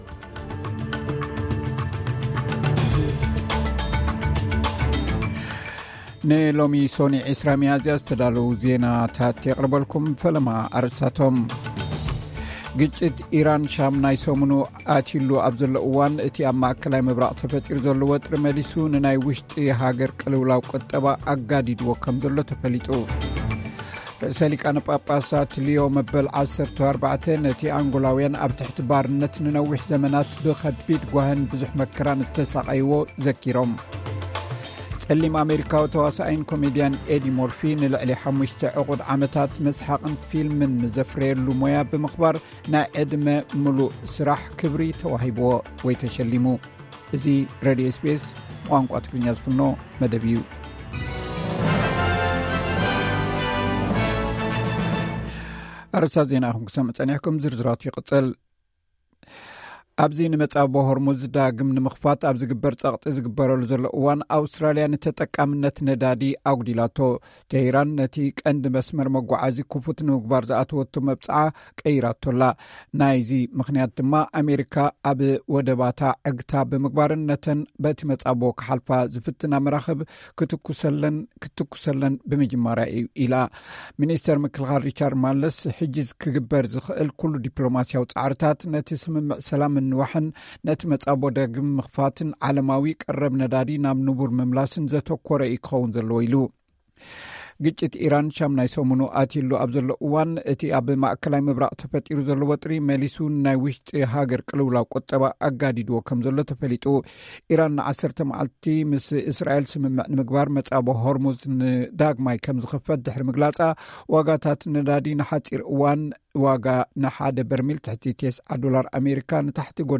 ዕለታዊ ዜና SBS ትግርኛ (20 ሚያዝያ 2026)